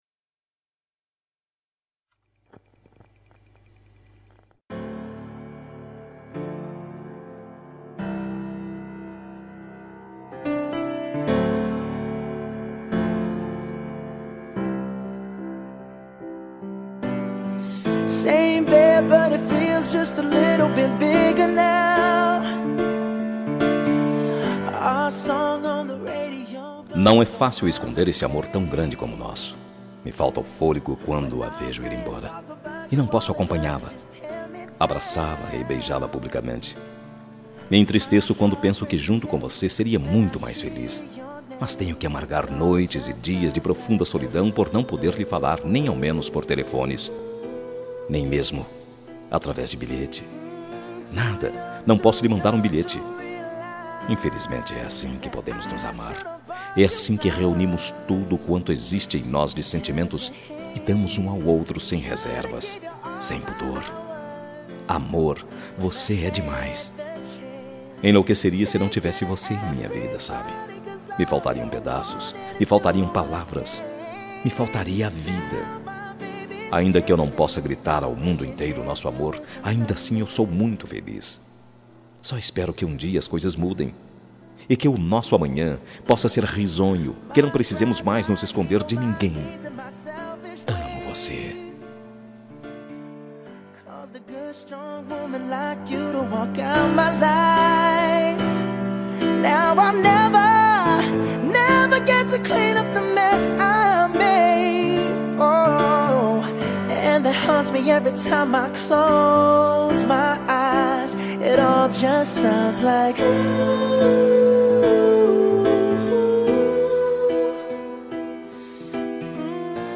Telemensagem Romântica para Ex. Voz Masculina – Cód: 201676